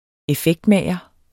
effektmager substantiv, fælleskøn Bøjning -en, -e, -ne Udtale [ eˈfεgdˌmæˀjʌ ] Betydninger 1. person der (erhvervsmæssigt) laver special effects til film- eller tv-produktioner Effektmager ..